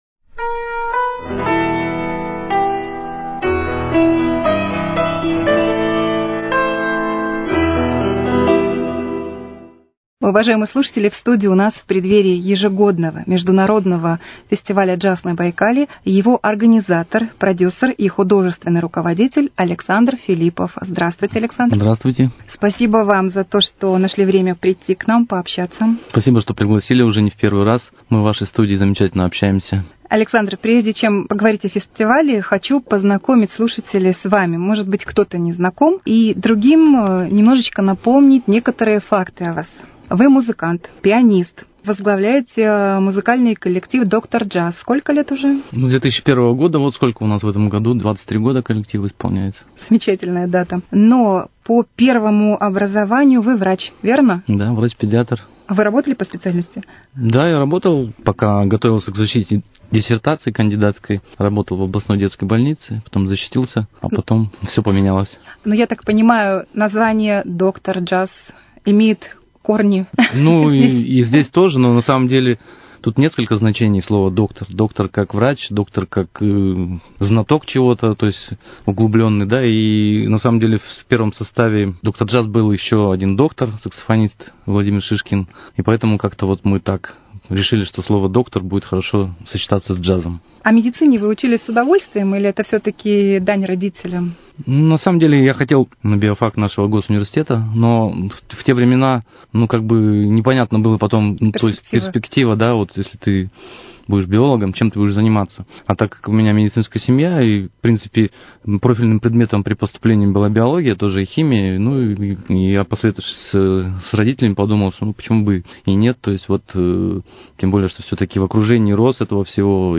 Музыкальный салон: Беседа